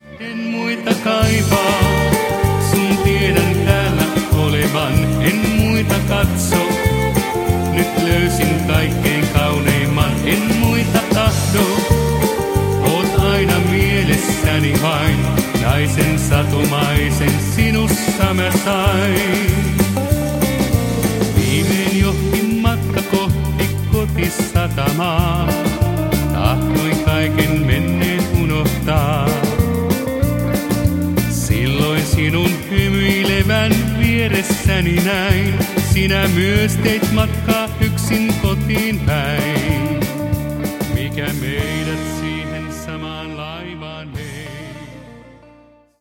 jalan alle käyvä twist tanssirytmi